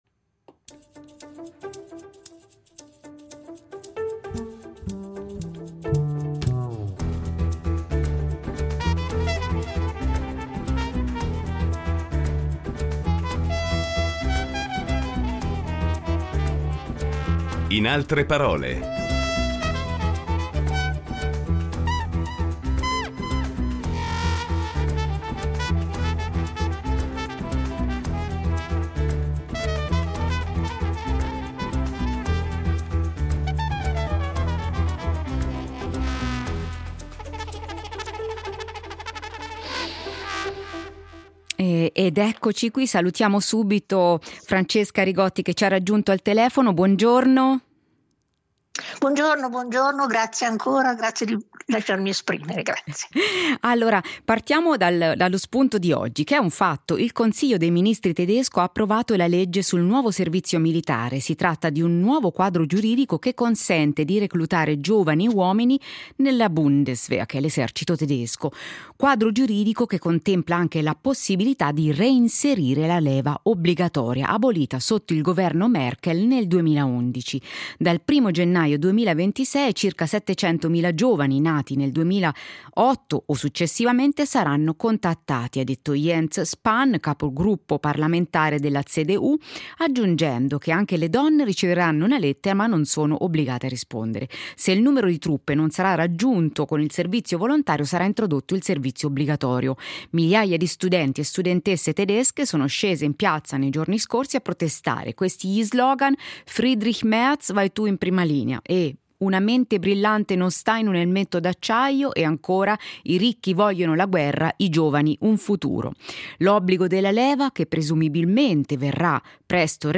La filosofa e saggista italiana ospite di “In altre parole”